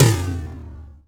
TOM MID S04R.wav